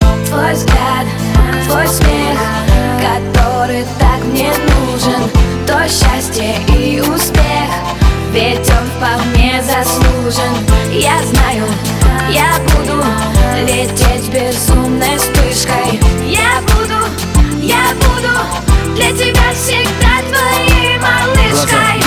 • Качество: 320, Stereo
поп
громкие
женский вокал
русская попса